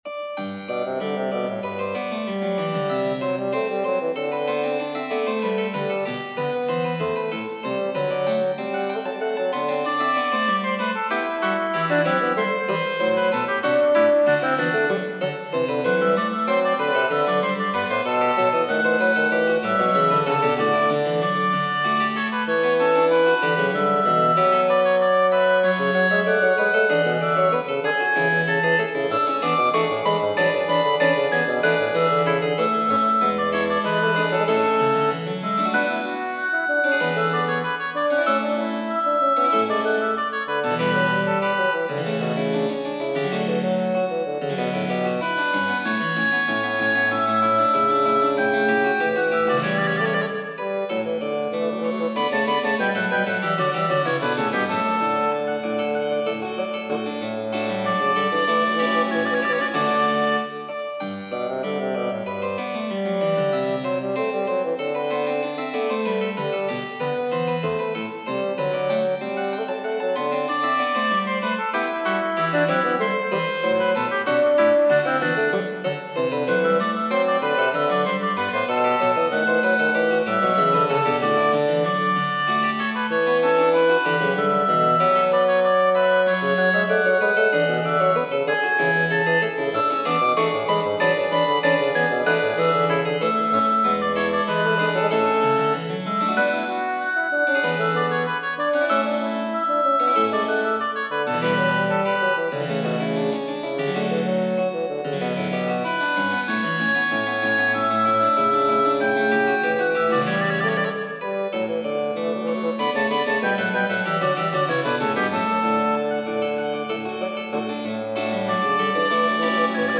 Trio Sonata work in progress
I have been listening to a lot of Baroque music lately, particularly the work of Zelenka.